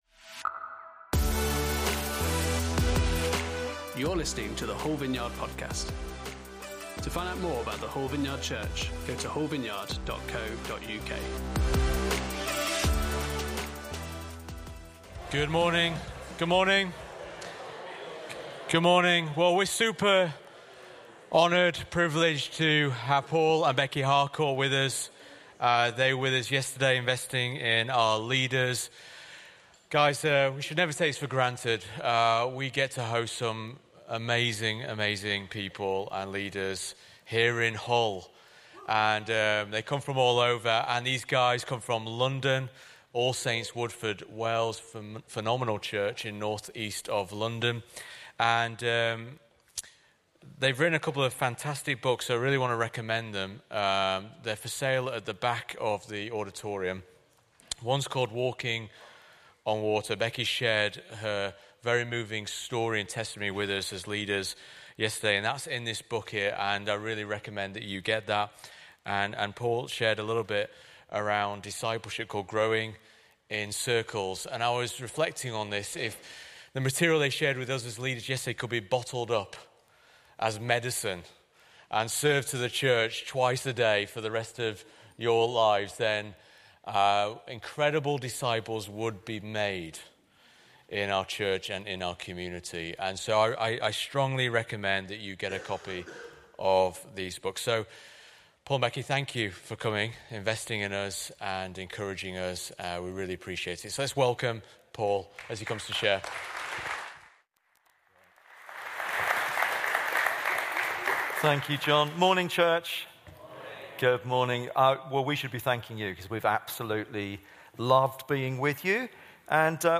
Guest Speaker
Service Type: Sunday Service